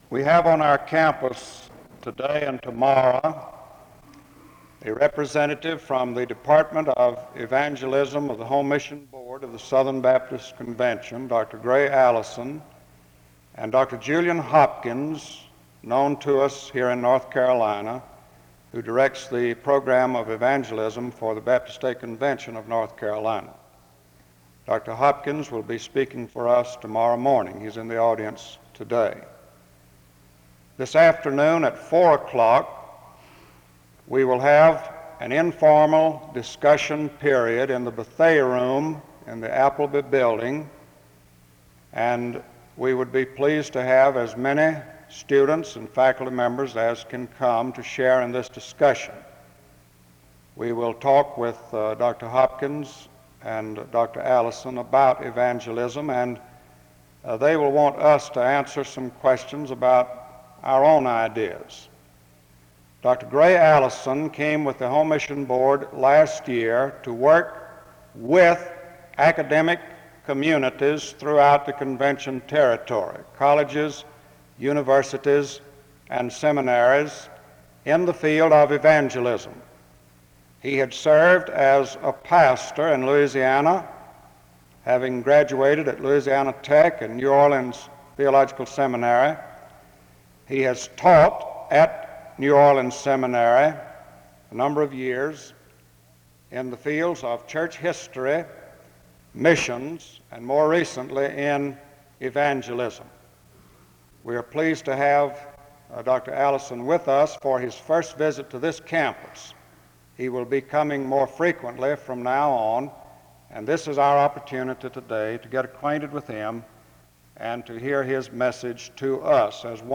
The service begins with an introduction to the speaker from 0:00-2:07.